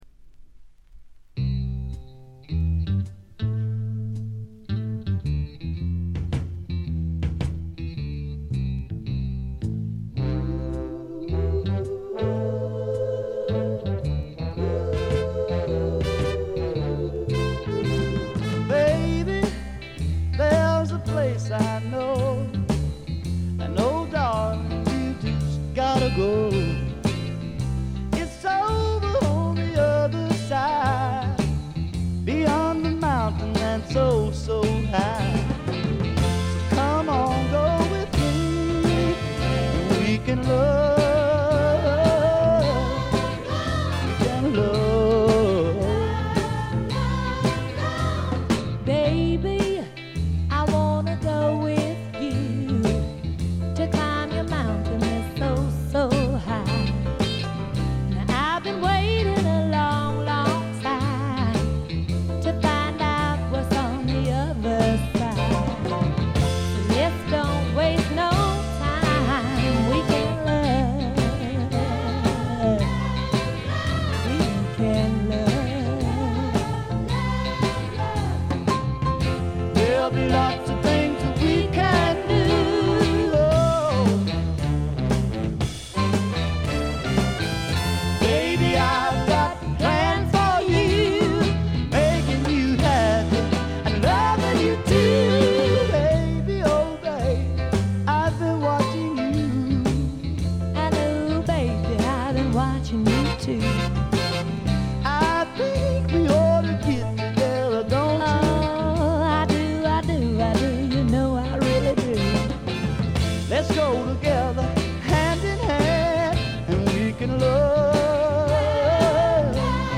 ほとんどノイズ感無し。
南部ソウル完璧な一枚。
試聴曲は現品からの取り込み音源です。